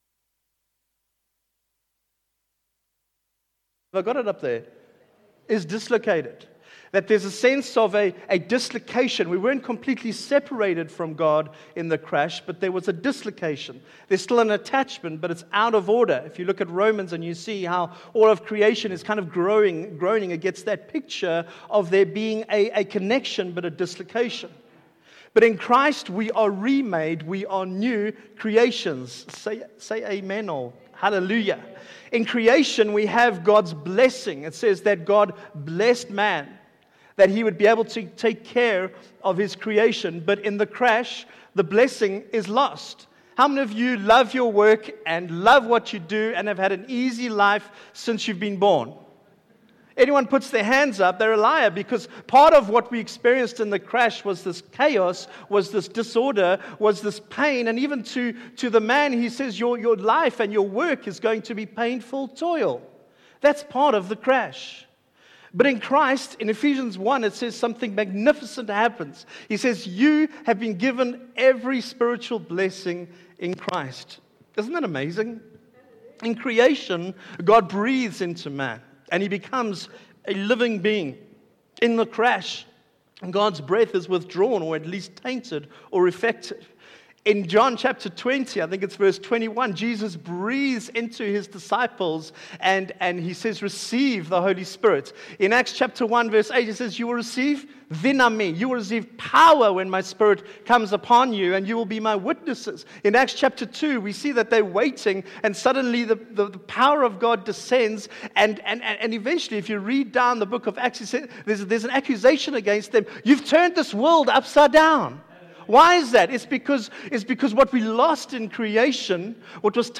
Sunday Service – 28 May
Life Givers Sermons